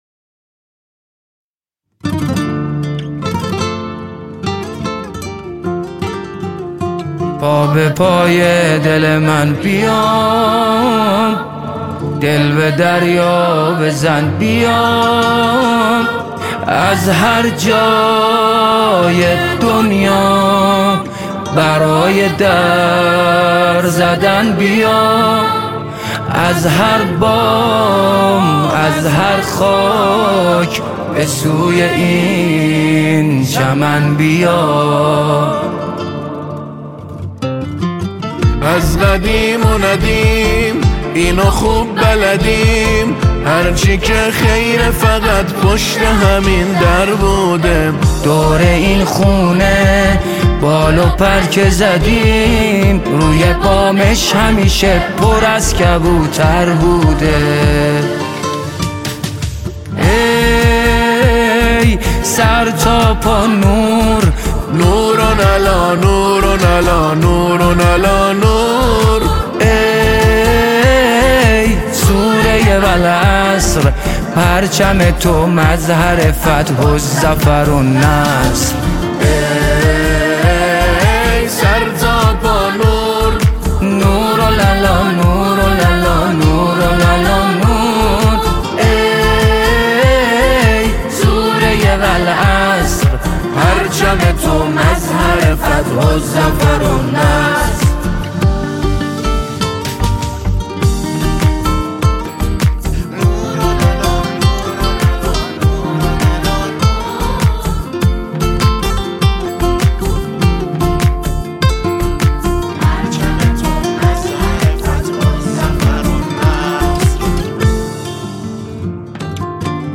ژانر: سرود ، سرود انقلابی ، سرود مذهبی ، سرود مناسبتی